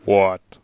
shaman_select5.wav